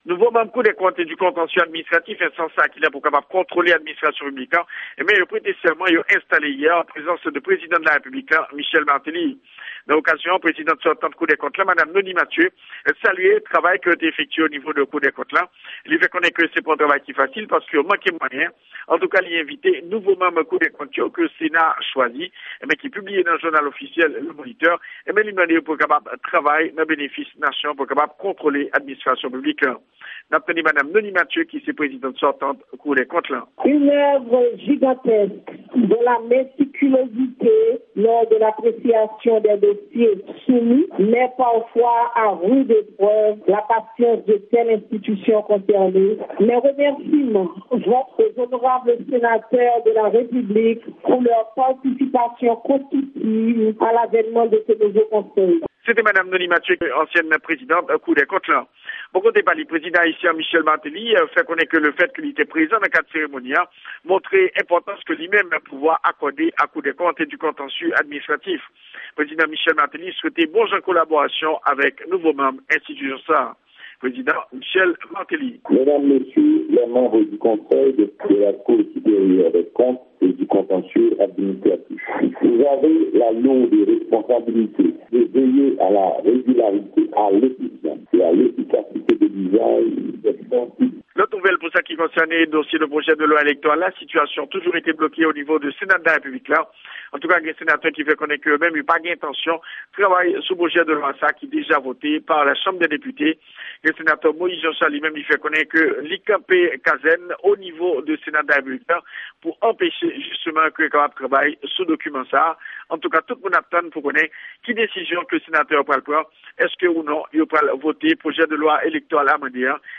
Repòtaj